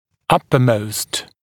[‘ʌpəməust][‘апэмоуст]самый верхний